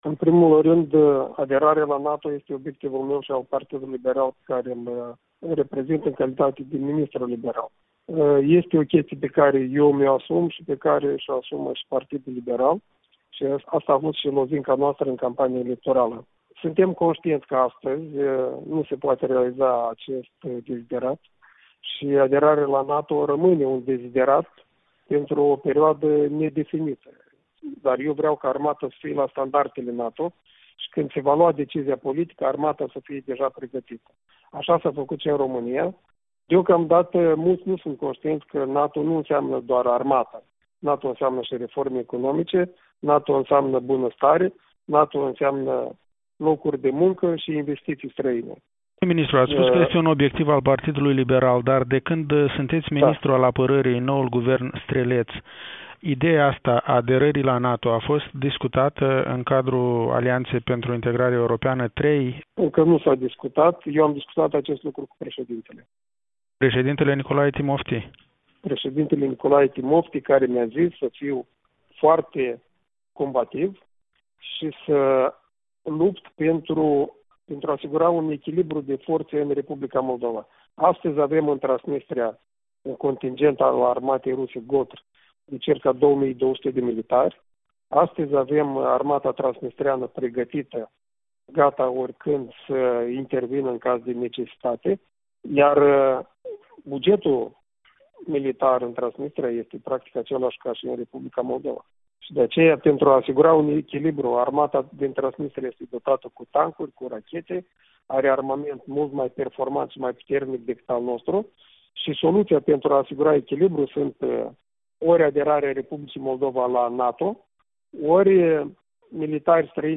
Interviu cu ministrul apărării din R.Moldova.